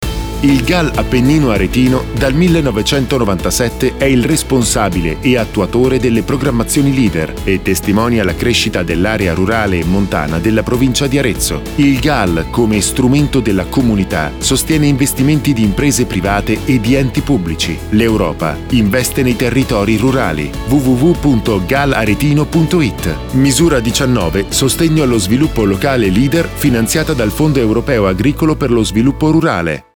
Radio Italia 5  spot n. 2